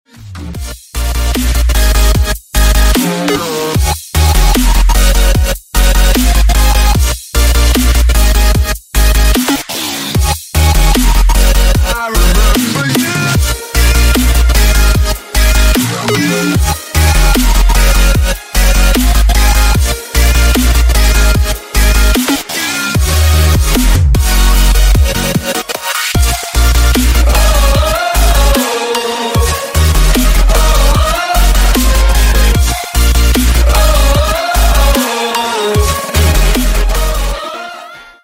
Громкие Рингтоны С Басами
Рингтоны Ремиксы » # Рингтоны Электроника